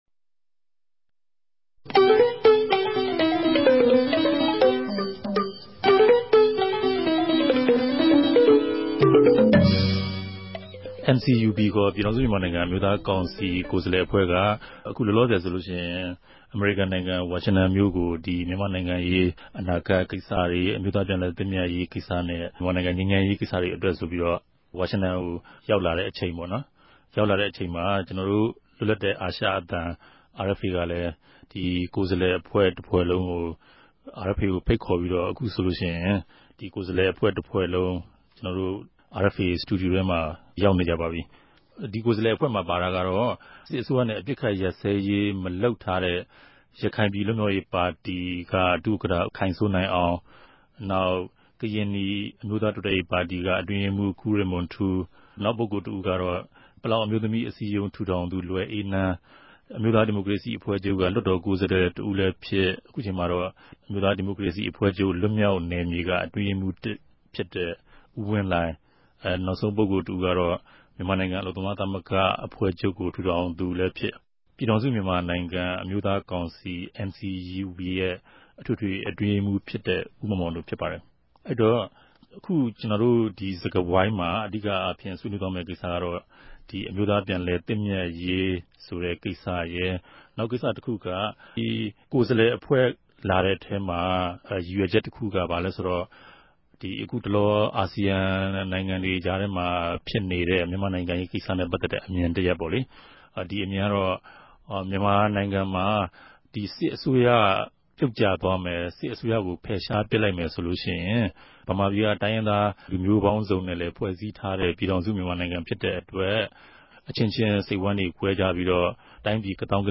လြတ်လပ်တဲ့အာရအြသံက၊ စတူဒီယိုထဲ ဖိတ်ခေၞူပီး၊
တပတ်အတြင်း သတင်းသုံးသပ်ခဵက် စကားဝိုင်း